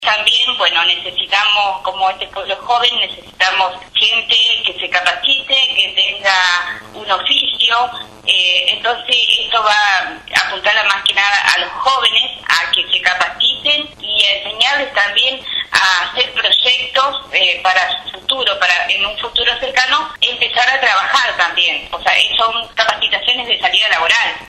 La presidenta comunal de San Bernardo, Saida Asan, dio detalles de las últimas novedades referidas a la localidad, destacando la importancia de las distintas iniciativas que están en marcha: construcción de dos viviendas, colocación de carteles nomencladores, puesta en marcha del programa Raíces, acciones para cuidar el medio ambiente, y también brindó un panorama referido a las recientes lluvias.